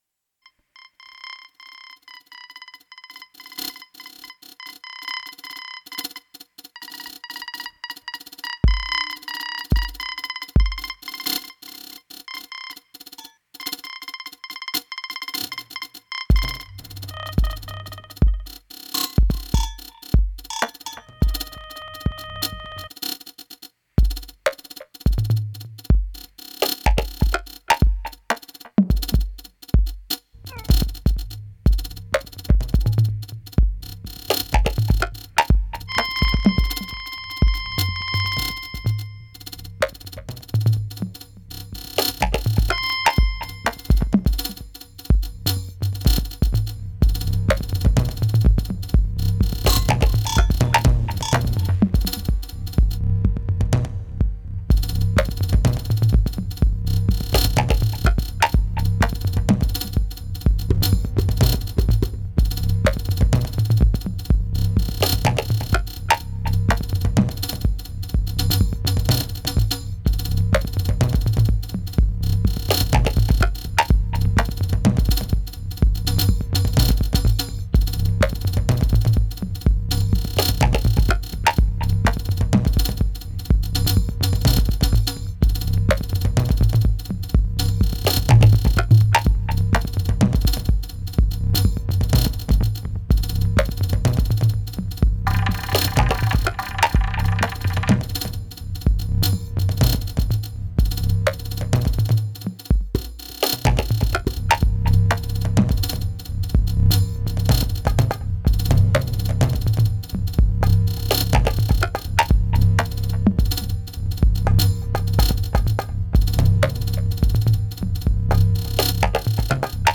Soundscapes floating over the horizon, aural illusions in broad daylight, distant voices transmitted.